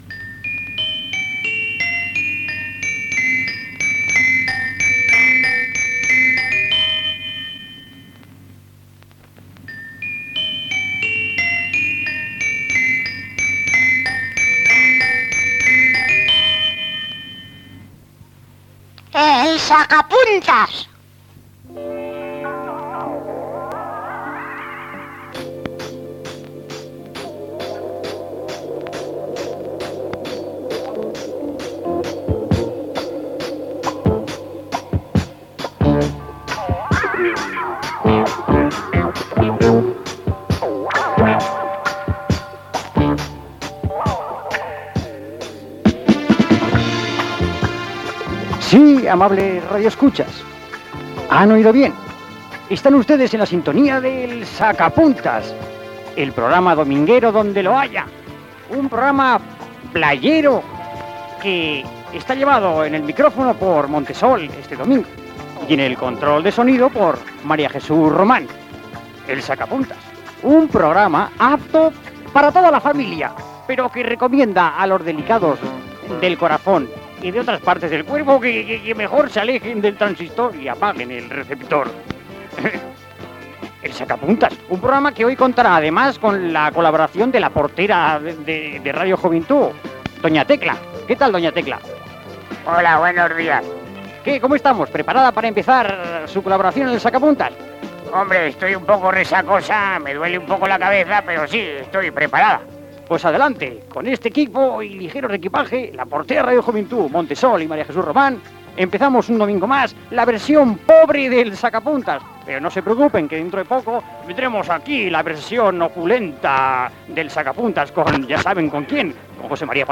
Sintonia de l'emissora, identificació del programa, presentació, equip.
Entreteniment
FM